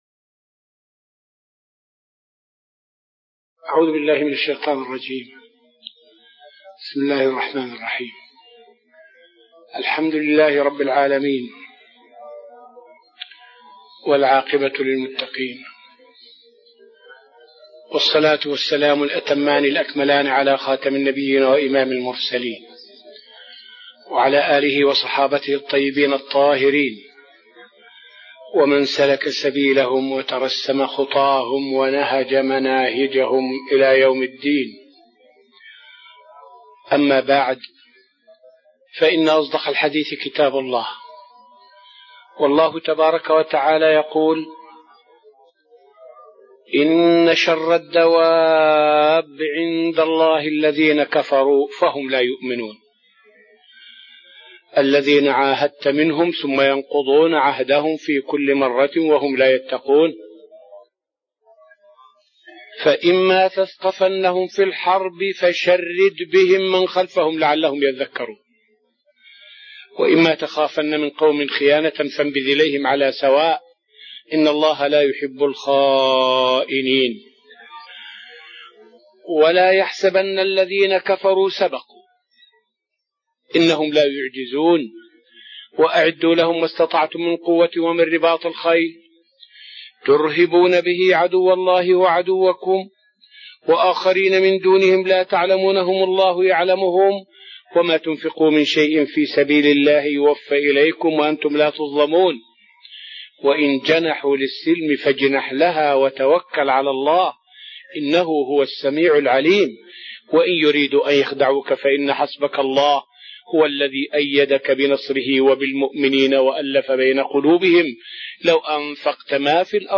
الدرس الرابع والعشرون من دروس تفسير سورة الأنفال والتي ألقاها الشيخ في رحاب المسجد النبوي حول الآيات من الآية 55 إلى الآية 60.